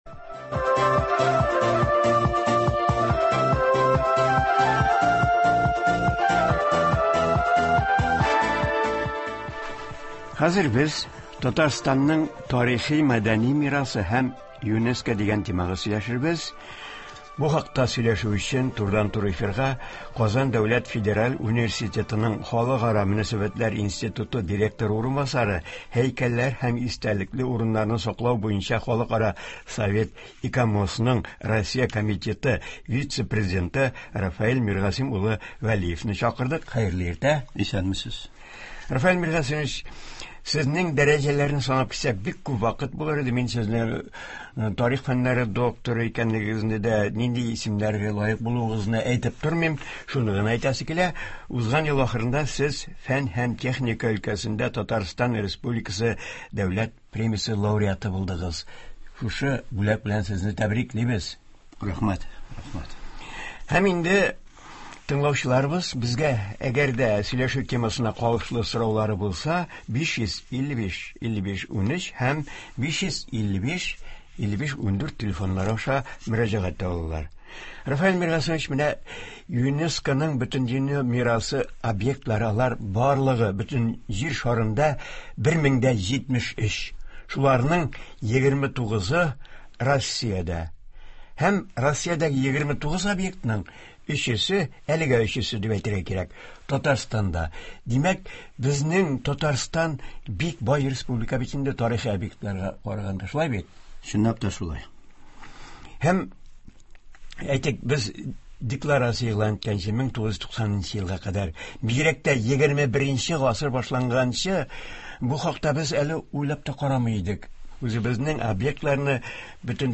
тыңлаучылар сорауларына җавап бирә.